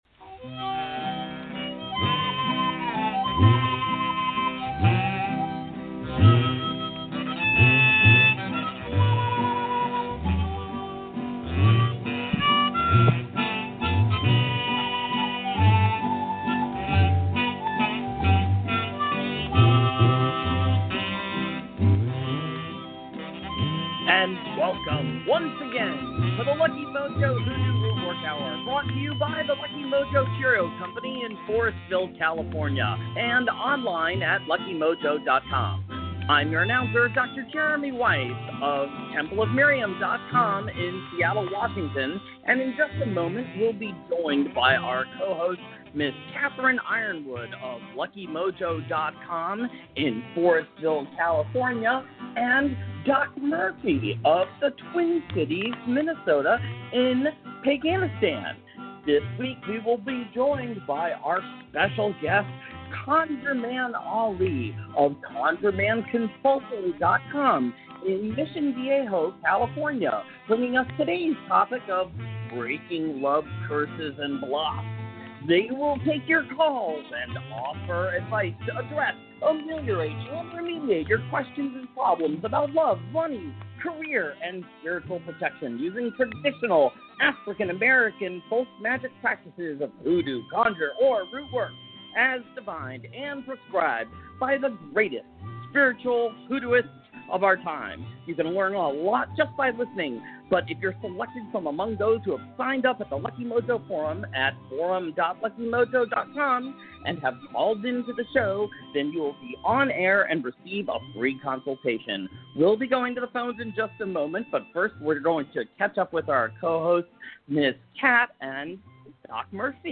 followed by free psychic readings, hoodoo spells, and conjure consultations, giving listeners an education in African-American folk magic.